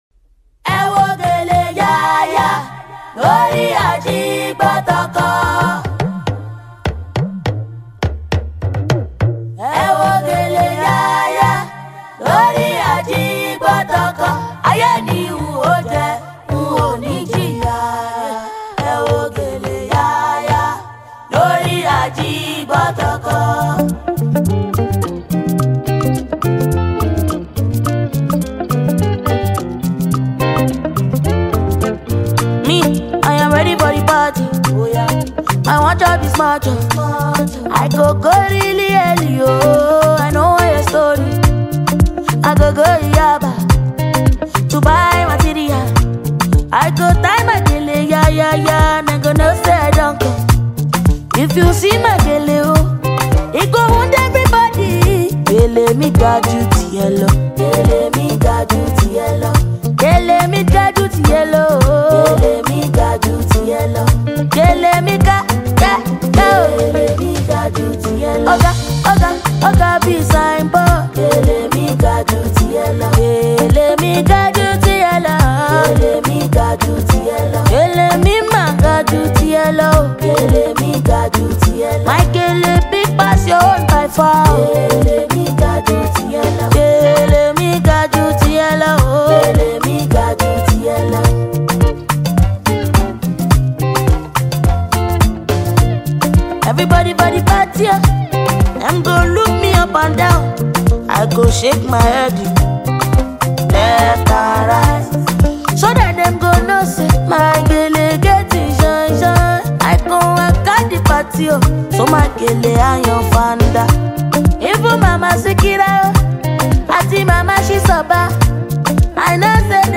yoruba infused vibe song